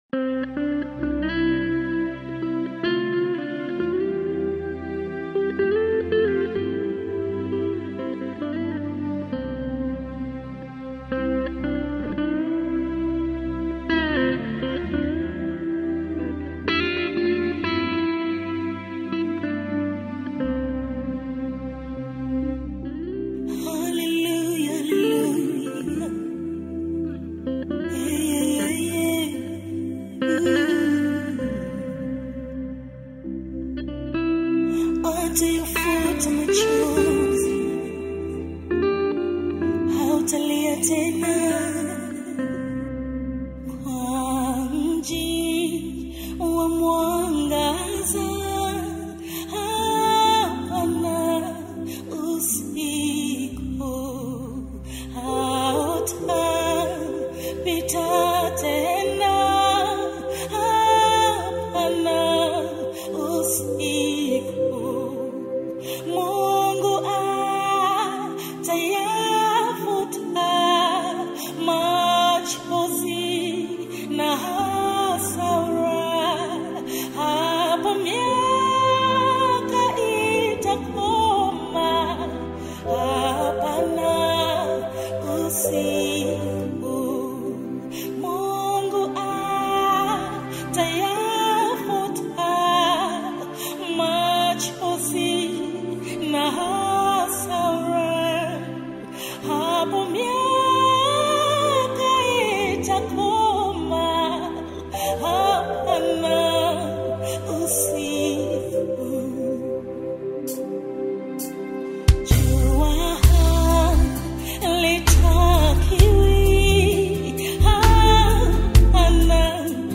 Gospel
African Music